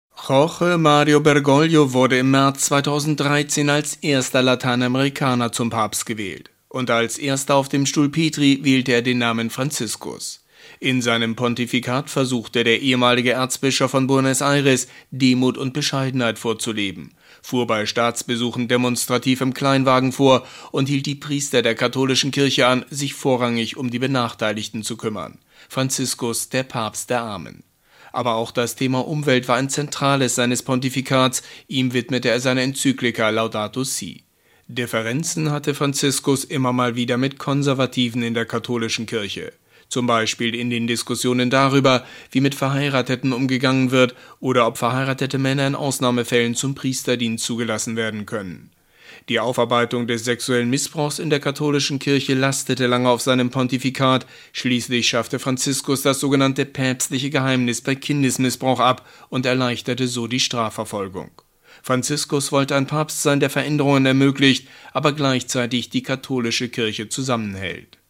Nachrichten Nachruf Papst Franziskus